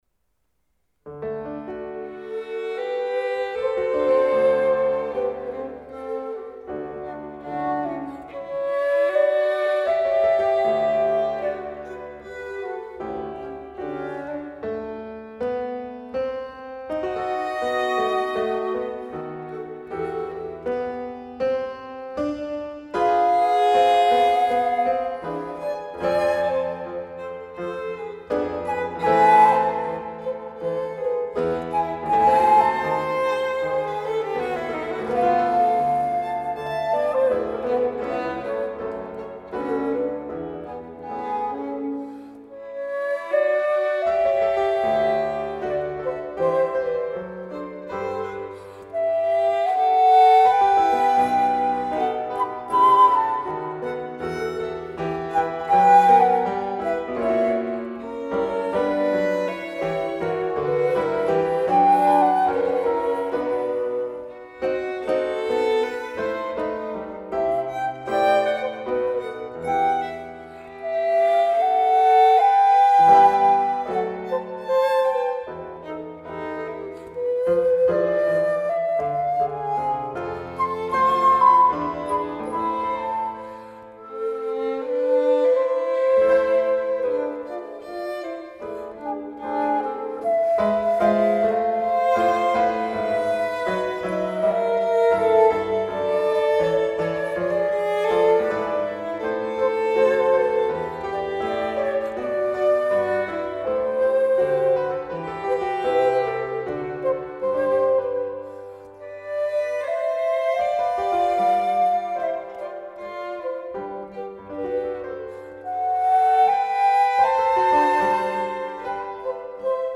HÖREN Silbermann-Hammerflügel, J.S.Bach Musicalisches Opfer BWV 1079, Concerto Madrigalesco,
Sonata sopr´il soggetto reale à traversa, violino e continuo, (III) Andante C